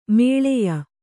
♪ mēḷeya